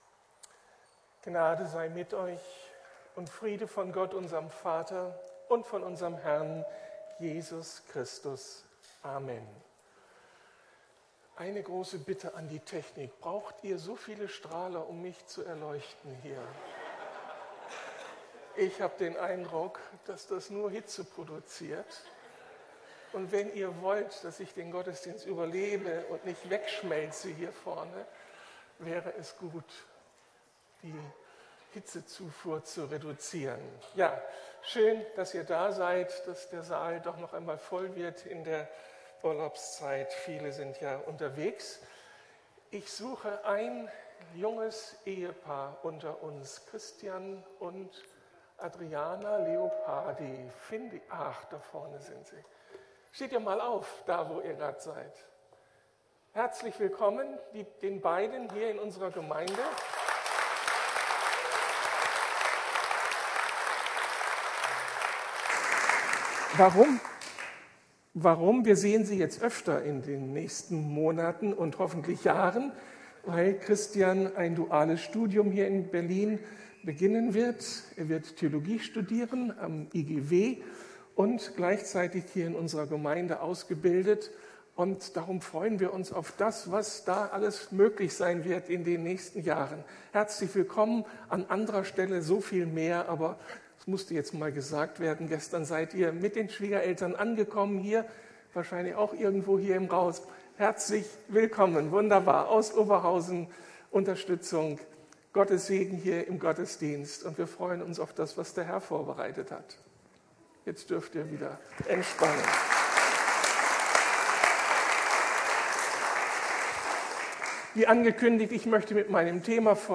Gott vertrauen in stürmischen Zeiten - Teil2 ~ Predigten der LUKAS GEMEINDE Podcast